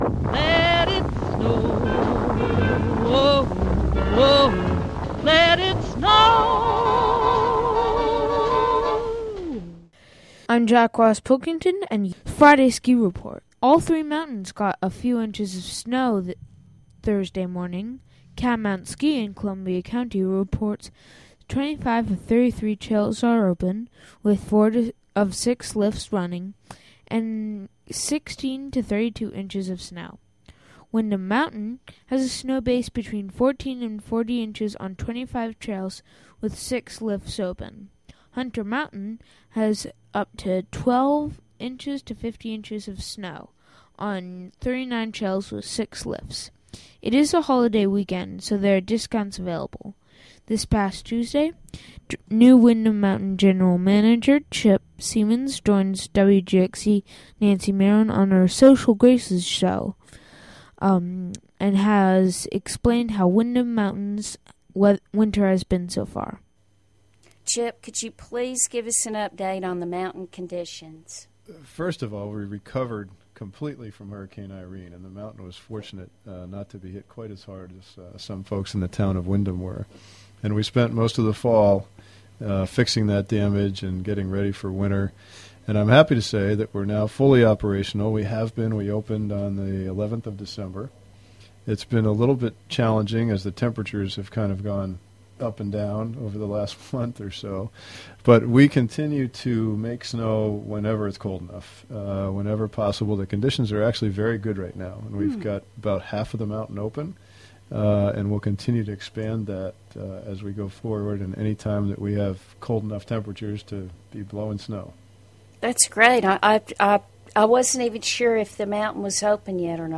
Includes interview